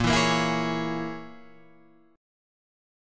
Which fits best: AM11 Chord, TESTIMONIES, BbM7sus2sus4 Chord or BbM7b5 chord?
BbM7b5 chord